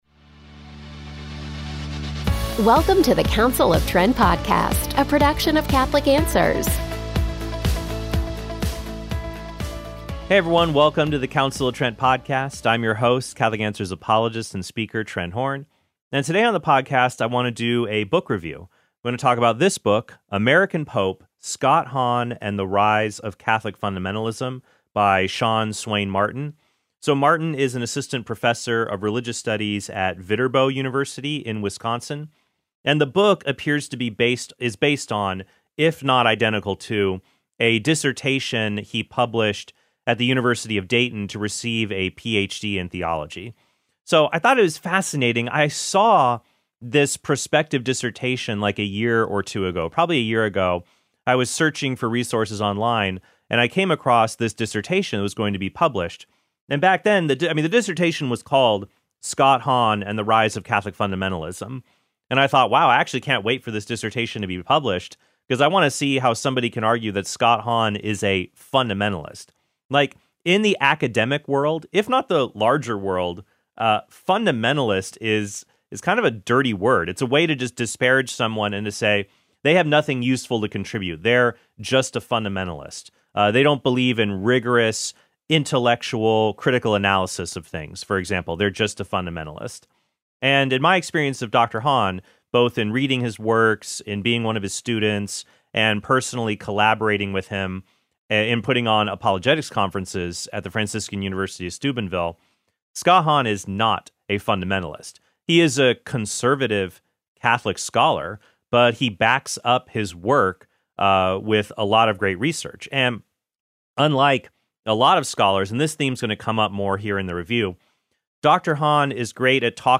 And today on the podcast, I want to do a book review.